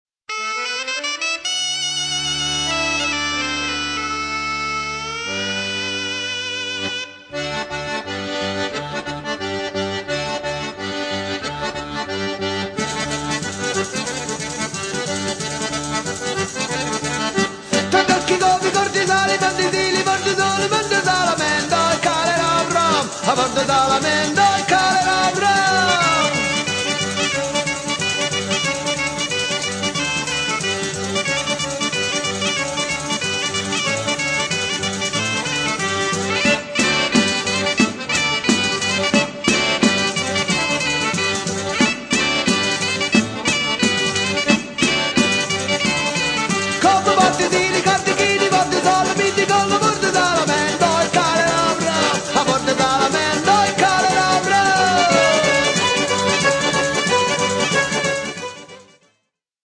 Genere: Musica Popolare